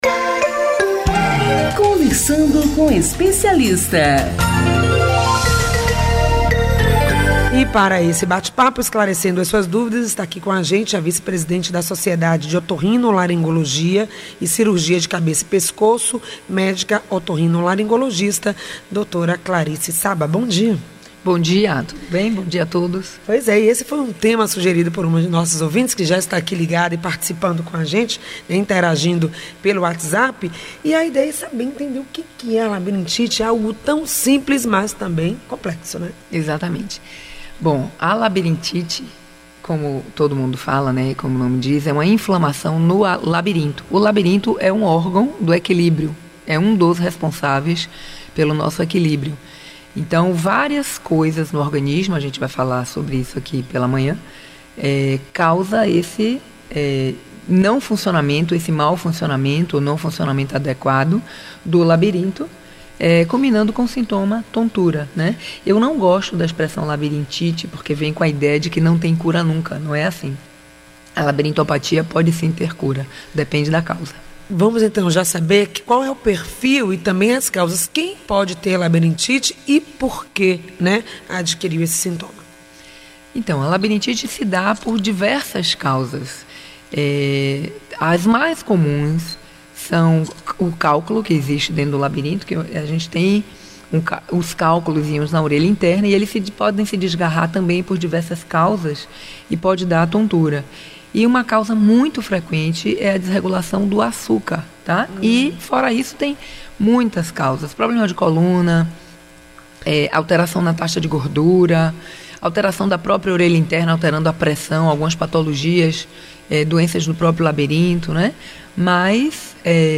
O programa exibido pela Rádio AM 840(em 05.05.16 das 8 às 9h) .